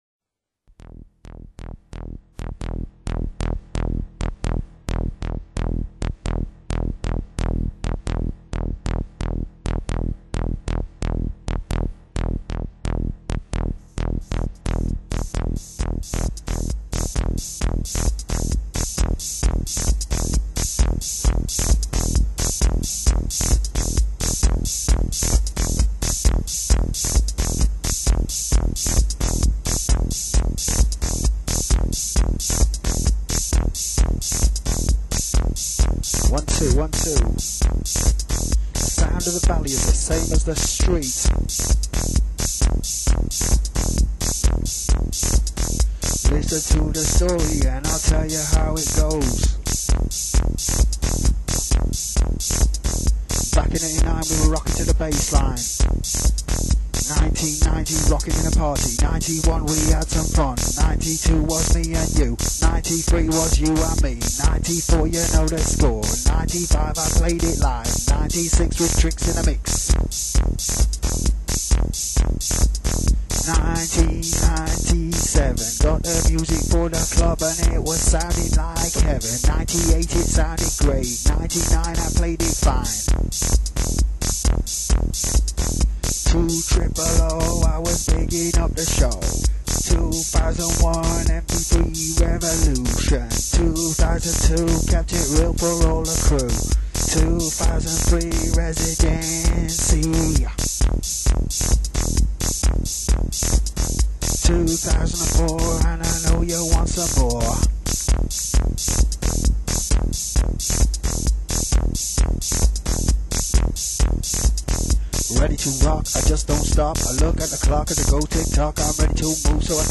rap music production made by the website administrator